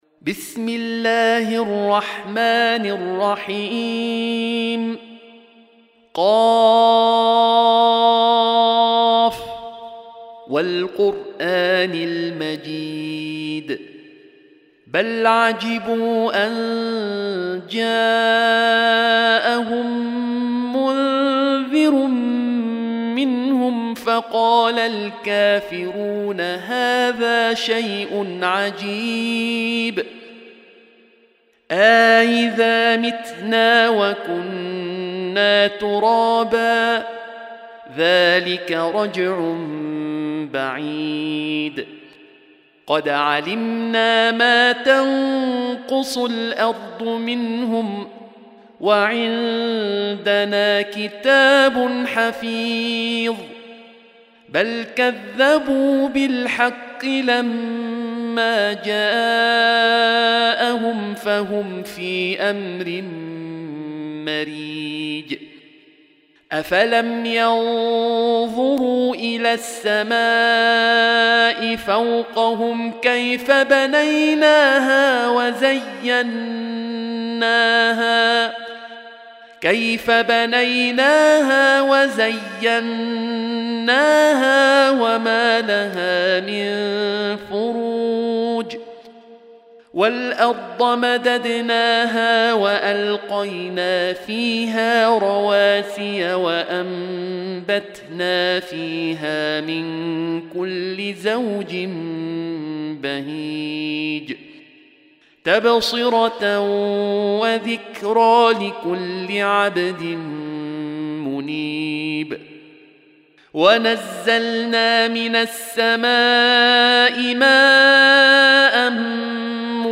Surah Sequence تتابع السورة Download Surah حمّل السورة Reciting Murattalah Audio for 50. Surah Q�f. سورة ق N.B *Surah Includes Al-Basmalah Reciters Sequents تتابع التلاوات Reciters Repeats تكرار التلاوات